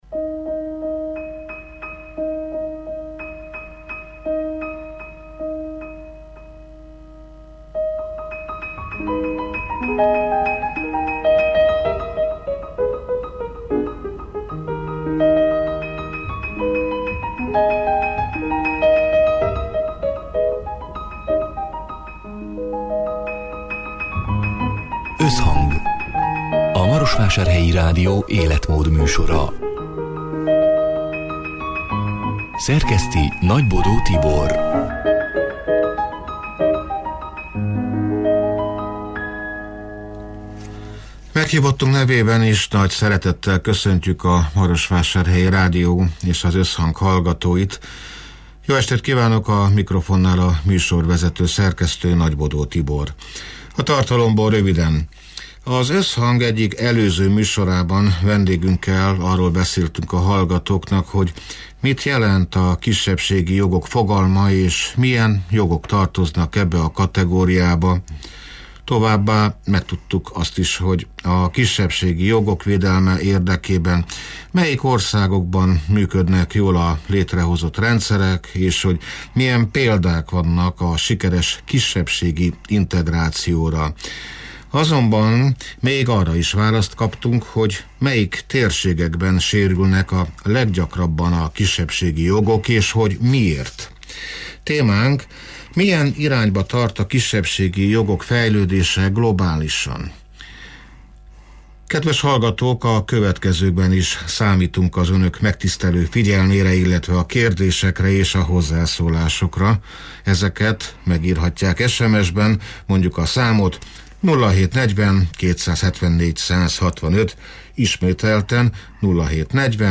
A Marosvásárhelyi Rádió Összhang (elhangzott: 2025. január 8-án, szerdán délután hat órától élőben) c. műsorának hanganyaga: Az Összhang egyik előző műsorában vendégünkkel arról beszéltünk a hallgatóknak, hogy mit jelent a kisebbségi jogok fogalma és hogy milyen jogok tartoznak ebbe a kategóriába.